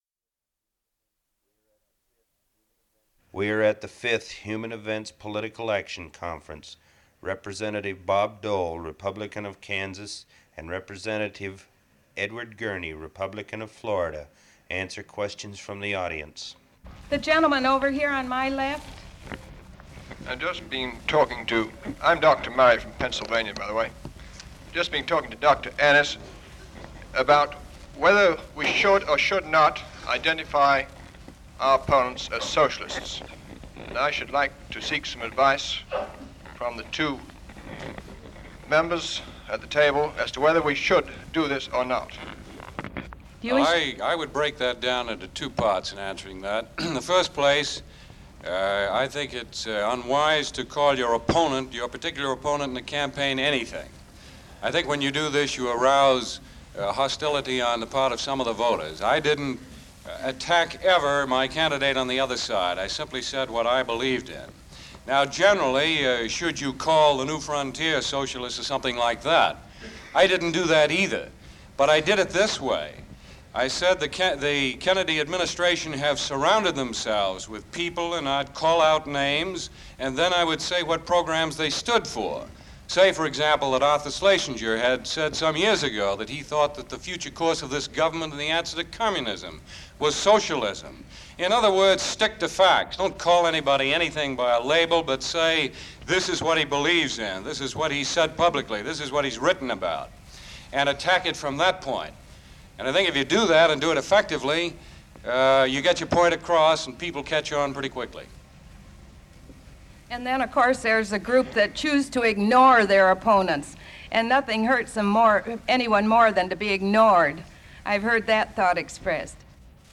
Part of Press Conference Featuring Edward Gurney & Bob Dole